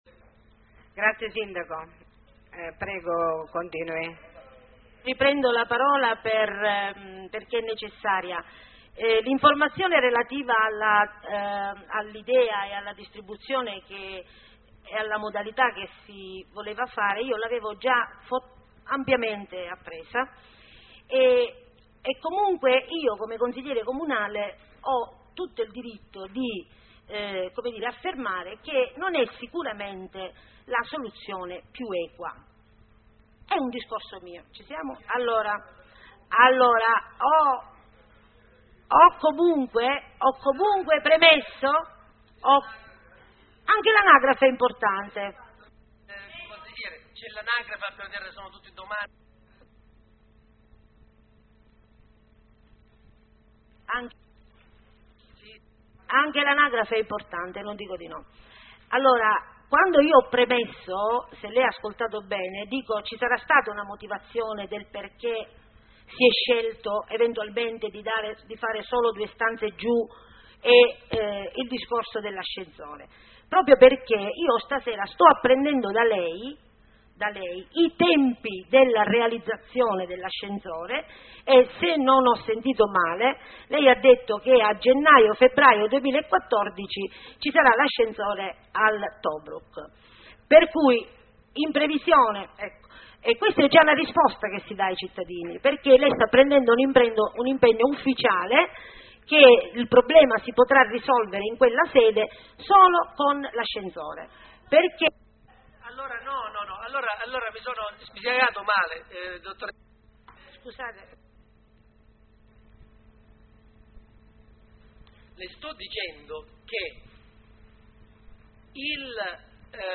7 consiglio comunale 29.10.2013 .mp3 seconda parte